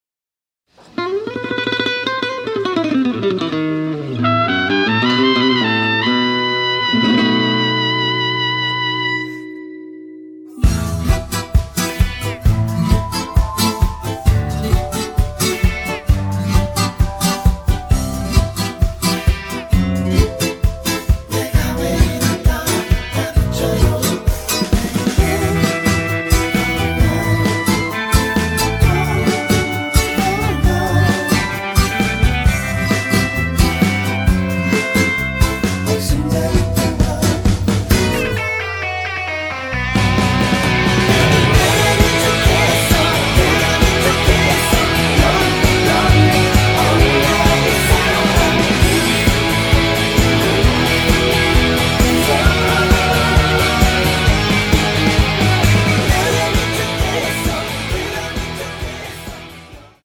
코러스 포함된 MR 입니다.
Em
앞부분30초, 뒷부분30초씩 편집해서 올려 드리고 있습니다.
중간에 음이 끈어지고 다시 나오는 이유는